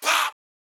Tm8_Chant6.wav